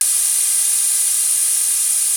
GasReleasing14.wav